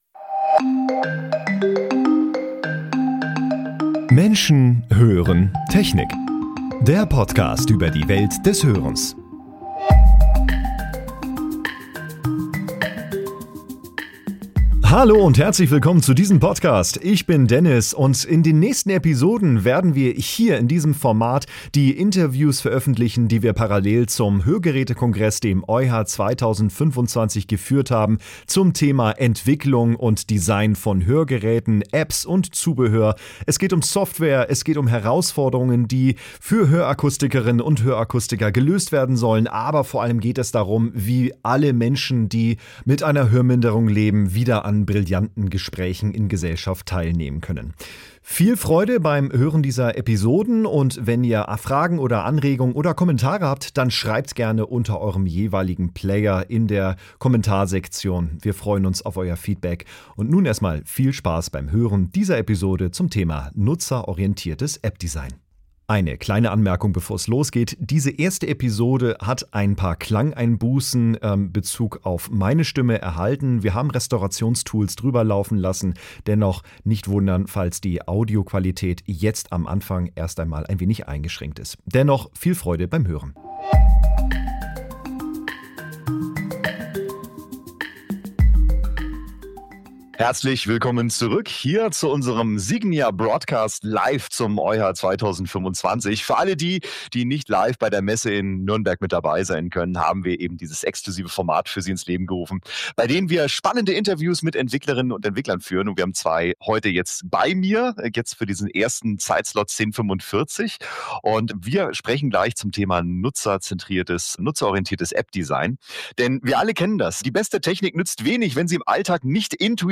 Wir freuen uns für unser erstes Gespräch Expertinnen bei uns zu haben, die genau diese Aspekte in den Mittelpunkt stellt: Wie schaffen wir es, dass sich Nutzer:innen in Apps nicht nur zurechtfinden, sondern sich verstanden und unterstützt fühlen? Dies ist Episode 1 der Interview-Reihe, die mit Entwicklerinnen und Entwicklern zum Thema Hörgeräte, digitale Services und Designs geführt wurde.